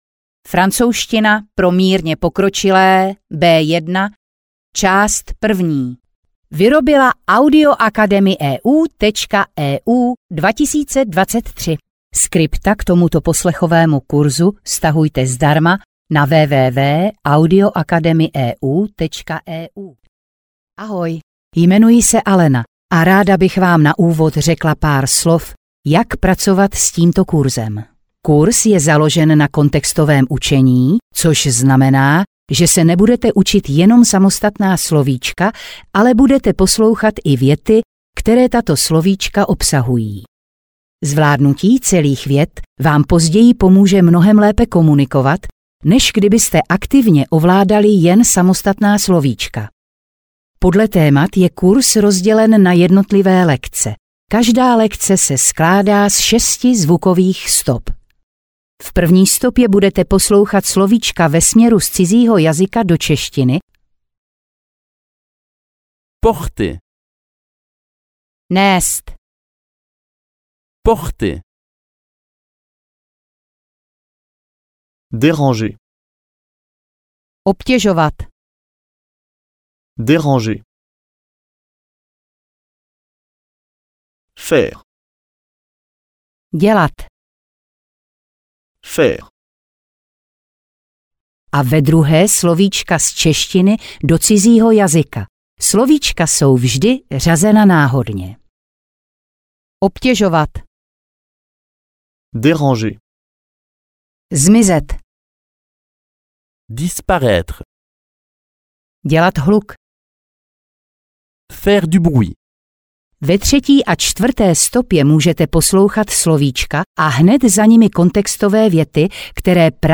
Francouzština pro mírně pokročilé B1 - část 1 audiokniha
Ukázka z knihy